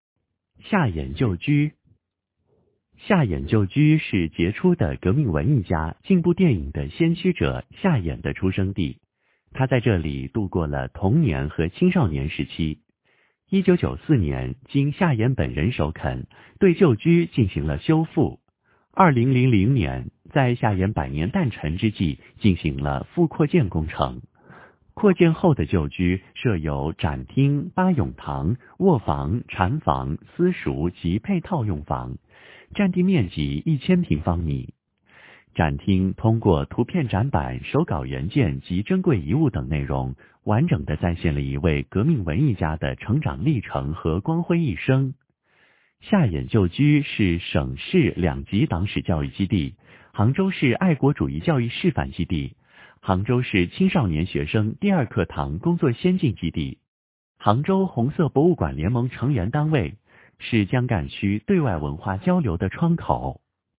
基本陈列展丨杭州夏衍旧居 发布时间：2020-06-09 16:01:56 语音解说： 夏衍旧居 杭州夏衍旧居位于杭州市江干区严家弄50号，是我国是杰出的革命文艺家、进步电影的先驱者夏衍的出生地。